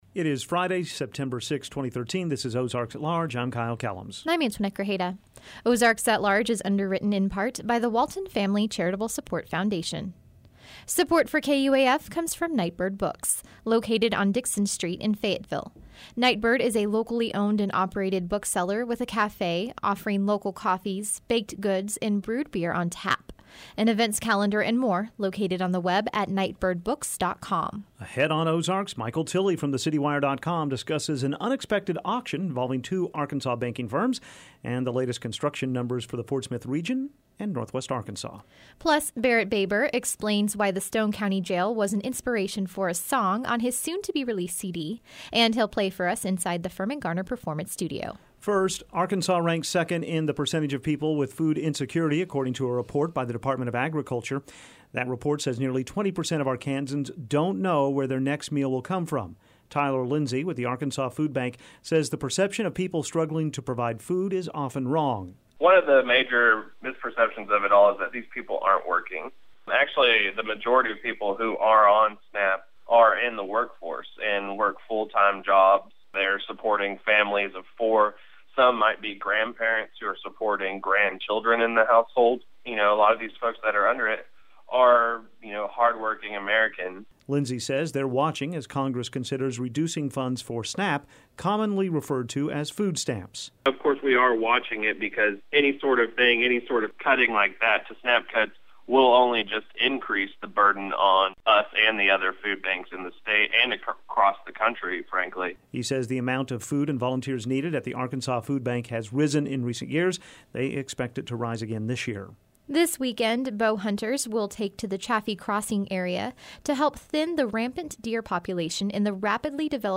and he'll play for us inside the Firmin Garner-Performance Studio.